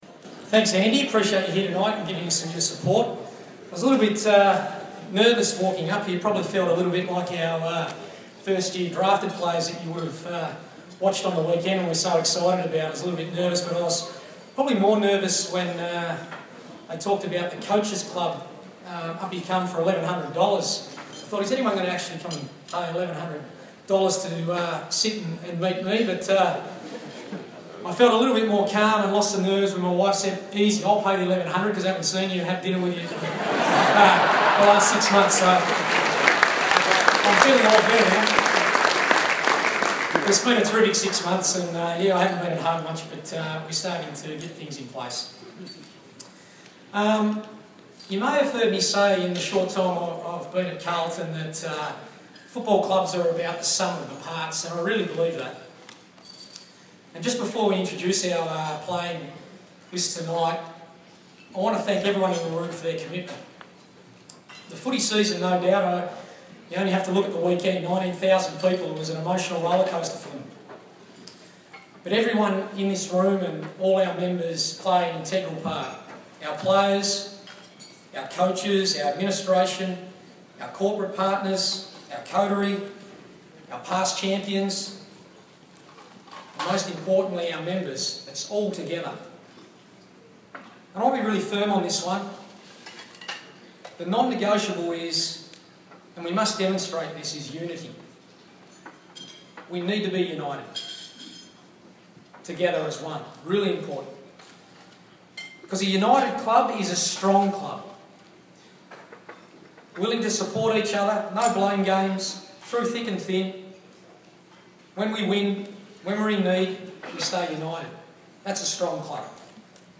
2016 Season Launch - Coach's speech
Coach Brendon Bolton addresses the faithful at the 2016 Carlton Football Club Season Launch, held at the Palladium at Crown.